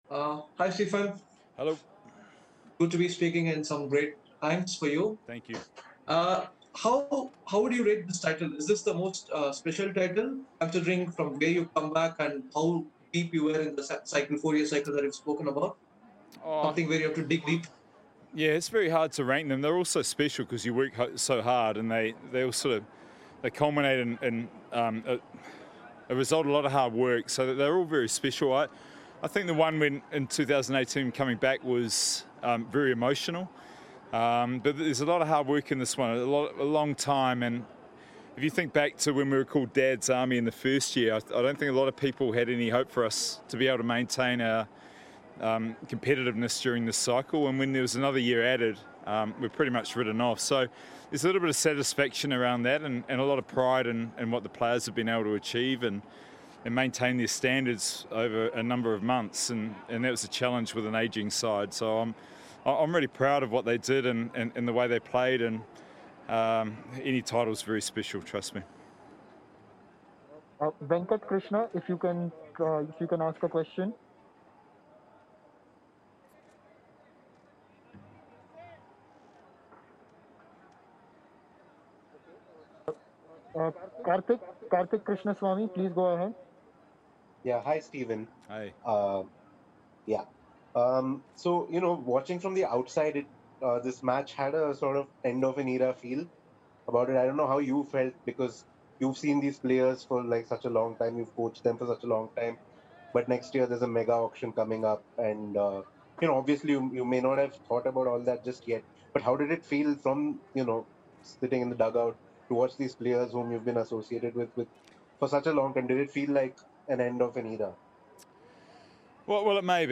Stephen Fleming addressed the media after Chennai Super Kings win the IPL 2021